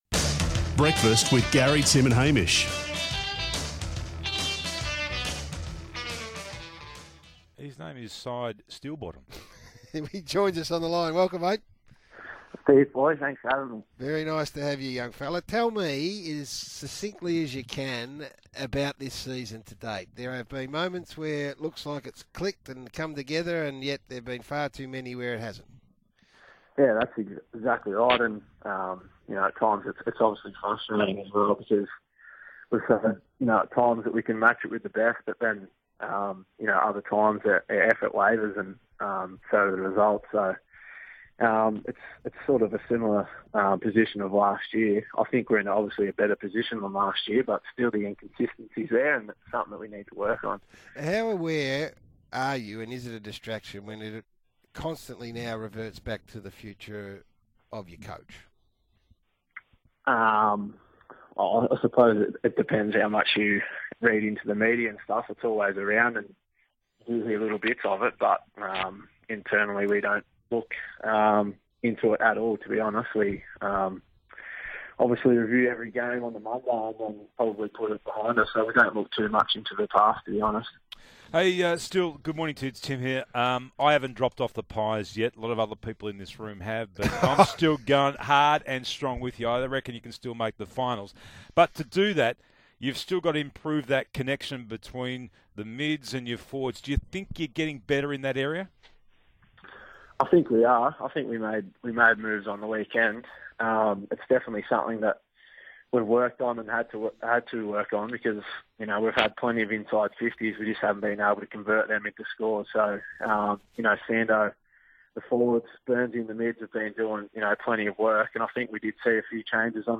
Listen to Collingwood vice captain Steele Sidebottom speak to the SEN Breakfast program on Thursday 19 May.